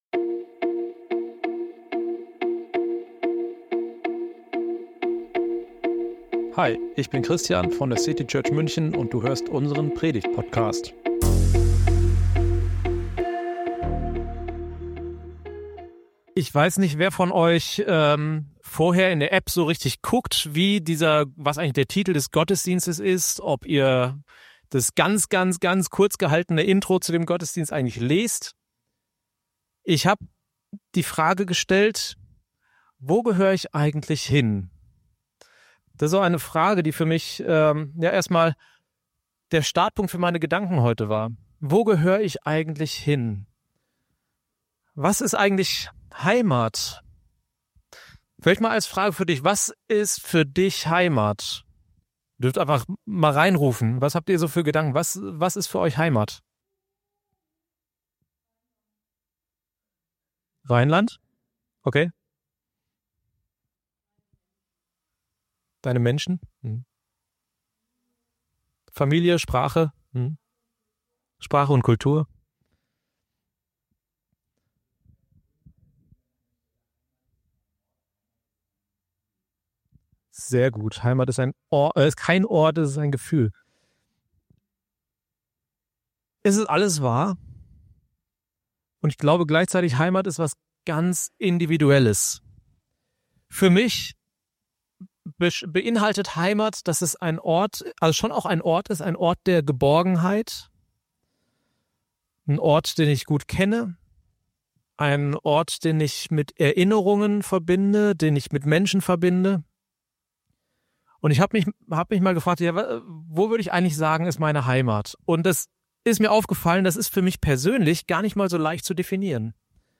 Wir wollen uns in diesem Gottesdienst Zeit zum Gebet nehmen. Psalm 23 ist uns dabei Inspiration und Anstoß zum Gebet.